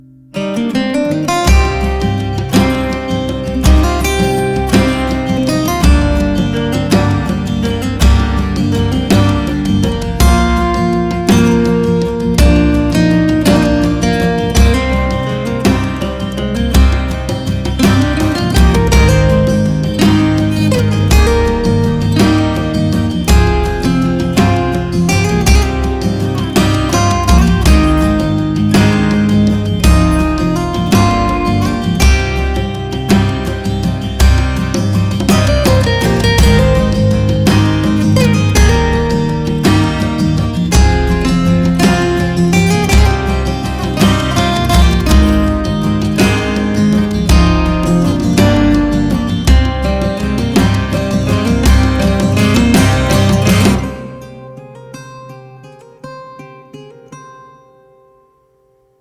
На гитаре